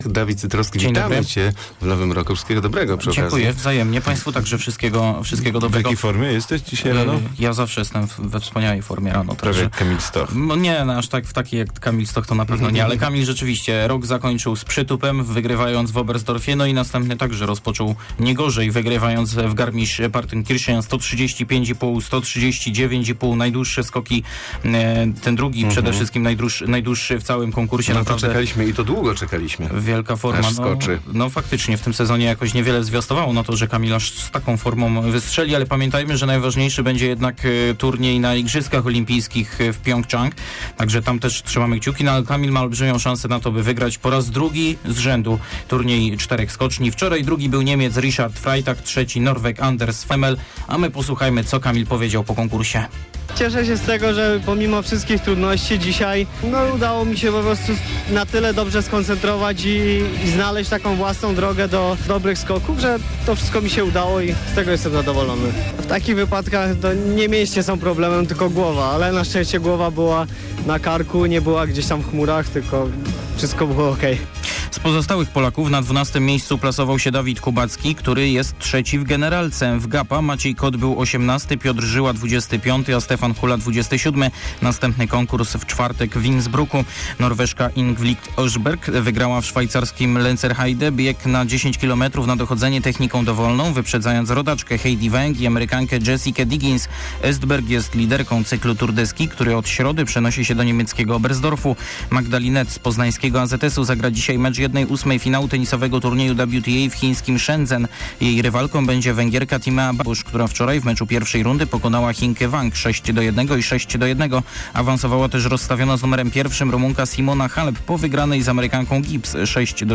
02.01 serwis sportowy godz. 7:45